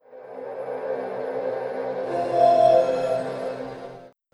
Samsung Galaxy S150 Startup.wav